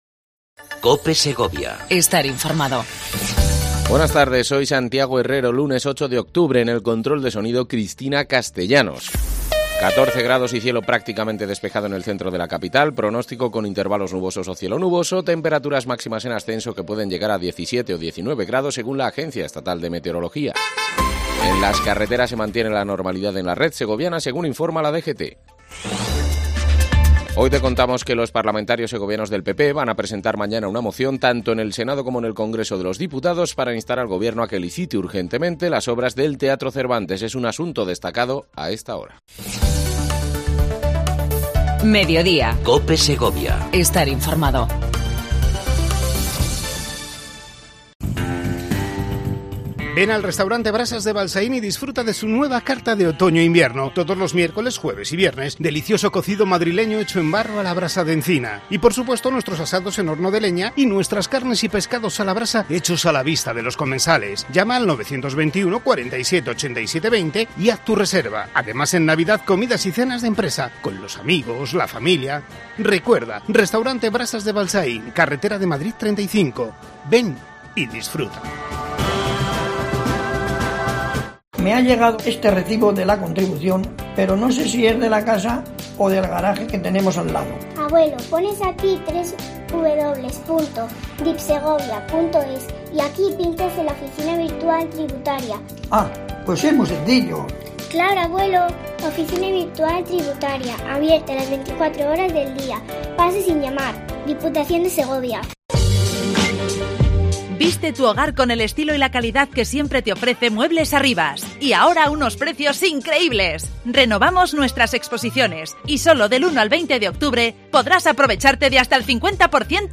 AUDIO: Entrevista mensual a la Alcaldesa de Segovia Clara Luquero